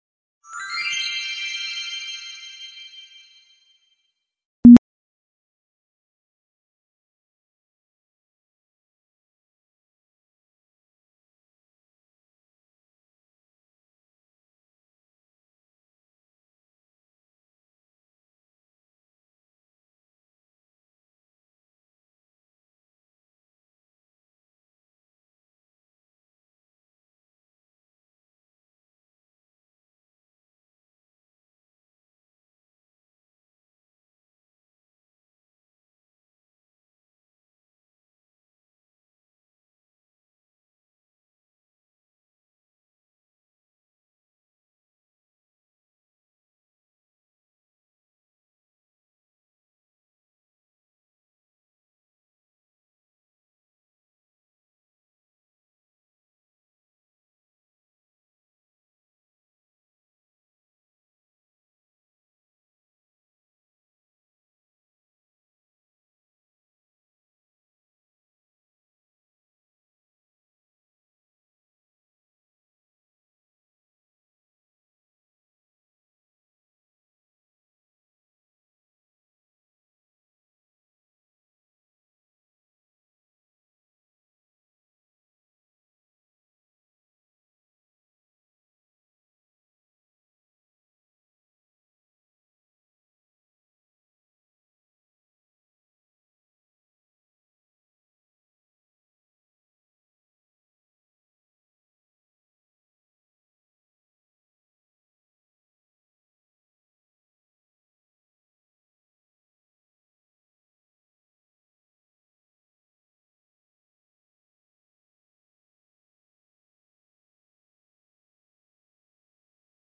¿Hay Política sin Estado? (Webconferencia 29-XI-2013,… | Repositorio Digital